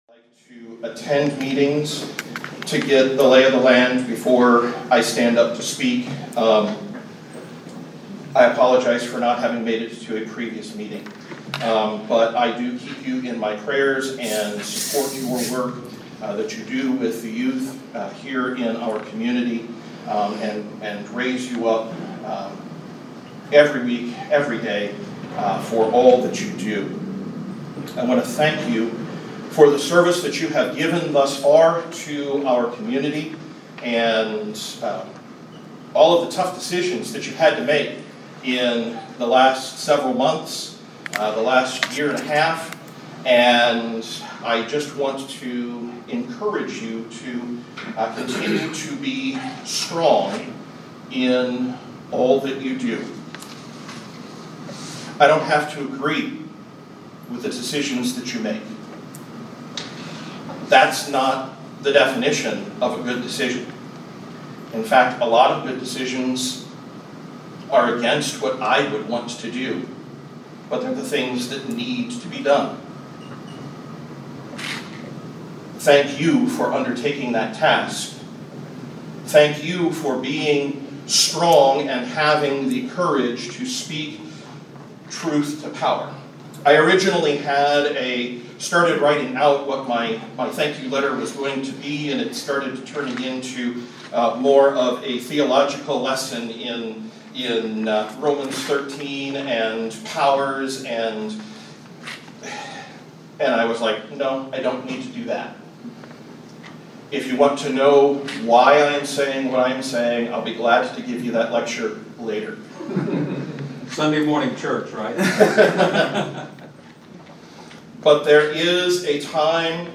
at the special Vandalia School Board meeting on Thursday.